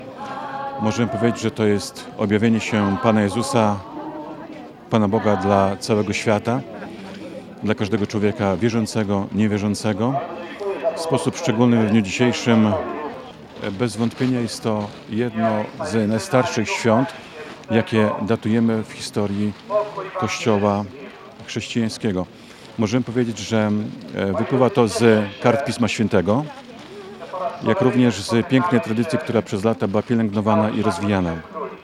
Ksiadz.mp3